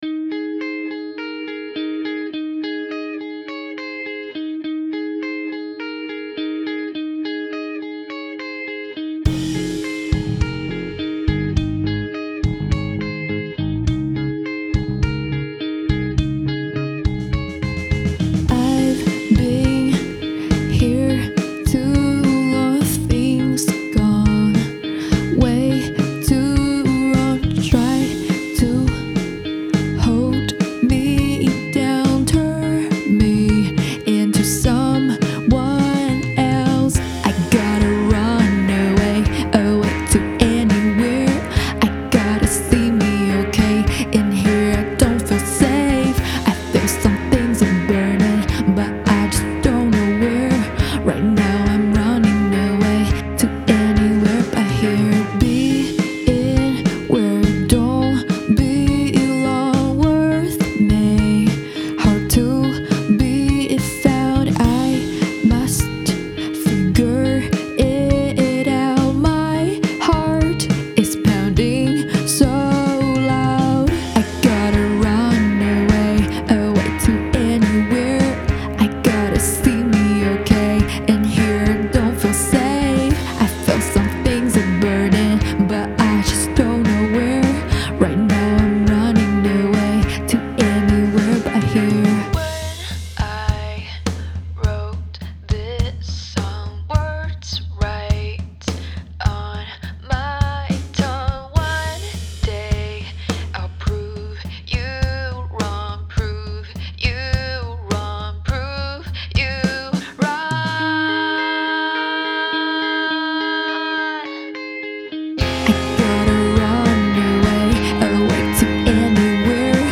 Genre: Pop-Rock
Version: Demo